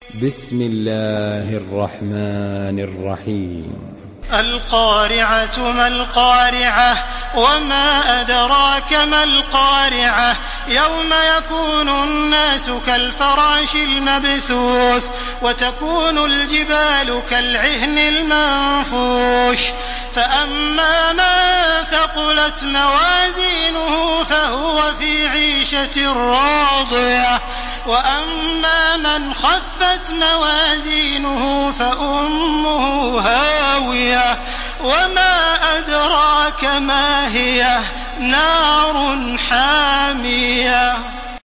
Makkah Taraweeh 1431
Murattal